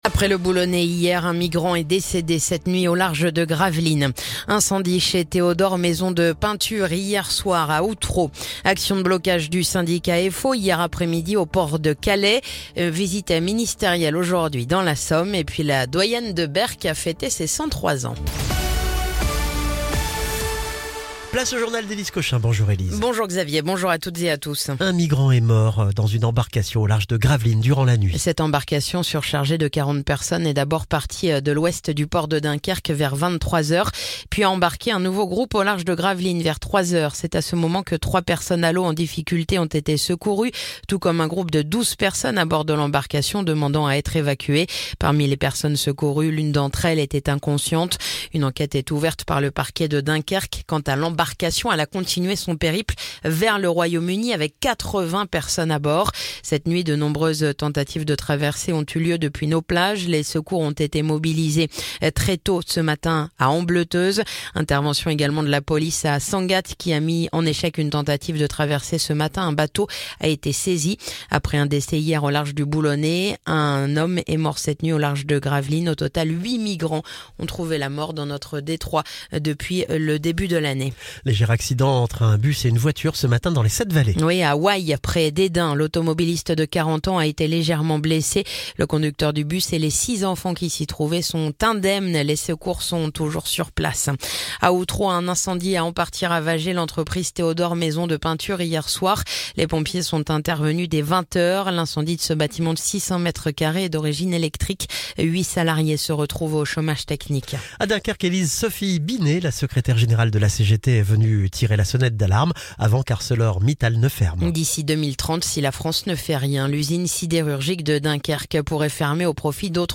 Le journal du jeudi 20 mars